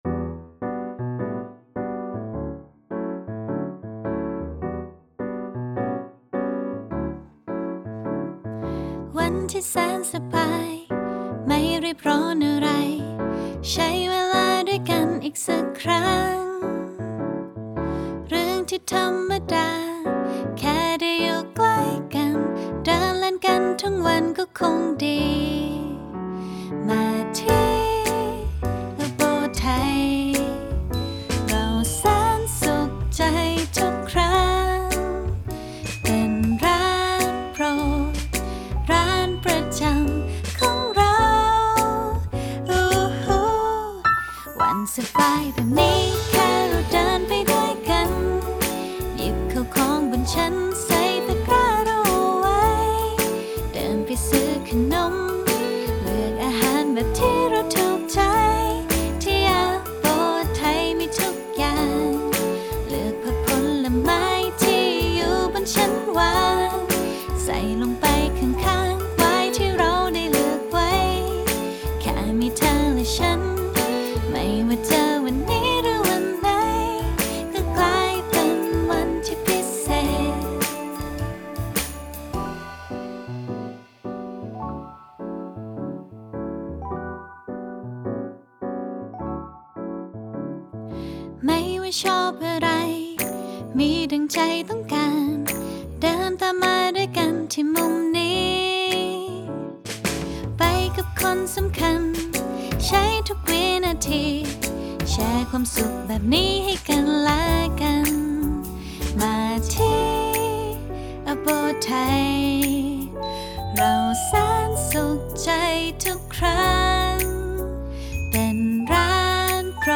3. เพลงความยาวมาตรฐาน 3 – 4 นาทีขึ้นไป
• โดยเพลงรูปแบบนี้ จะประกอบ “ท่อนเพลง” ตามมาตรฐาน เช่น